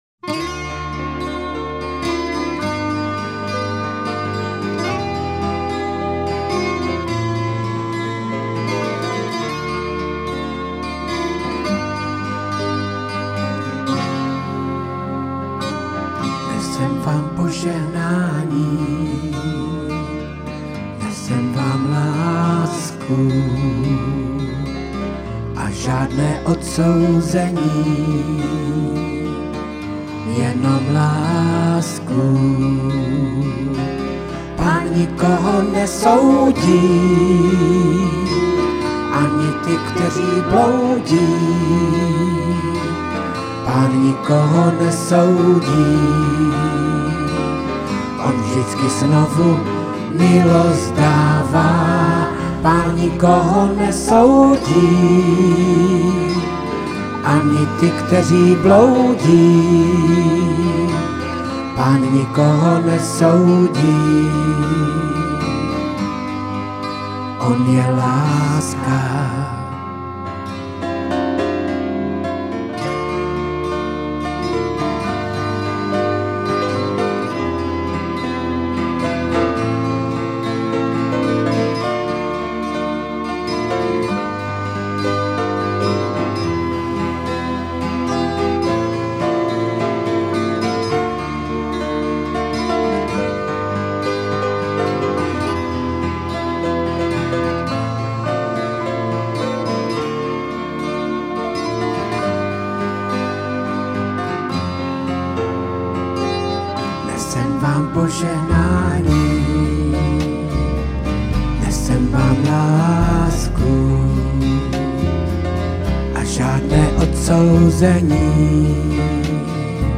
Křesťanské písně
Evangelizační písně